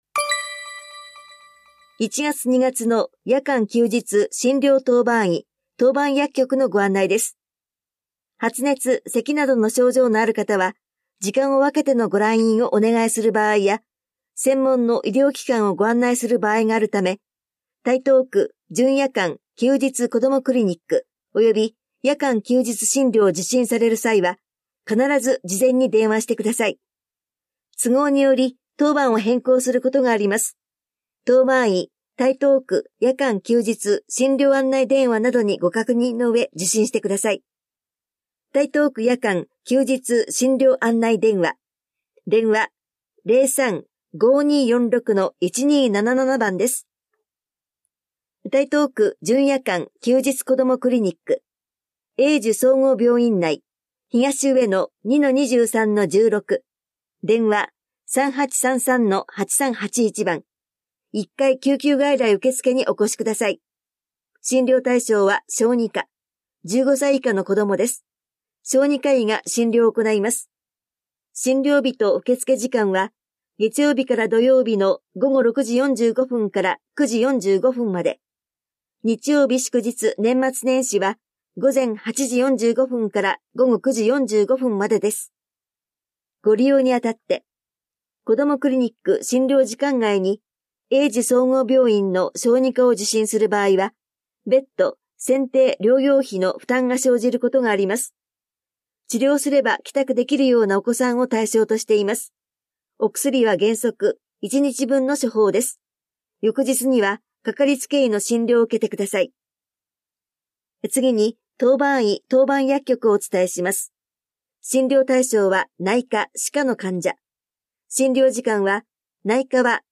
広報「たいとう」令和6年1月1日号の音声読み上げデータです。